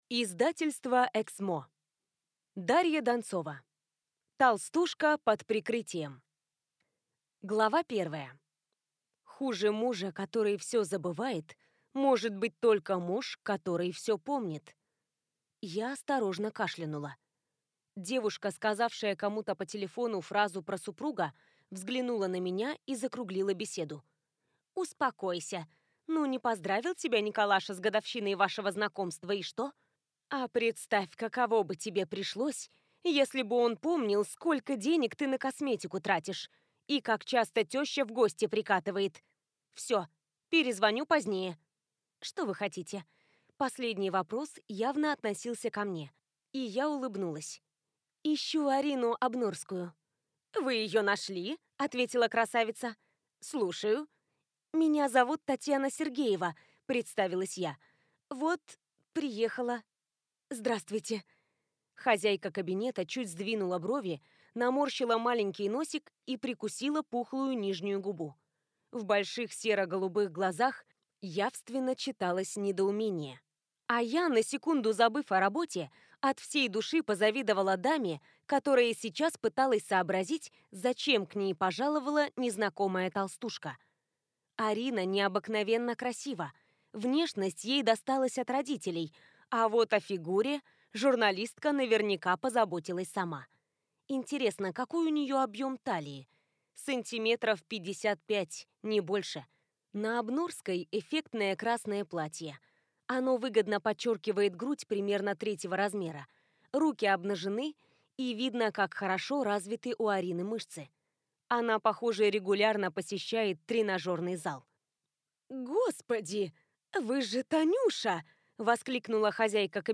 Аудиокнига Толстушка под прикрытием | Библиотека аудиокниг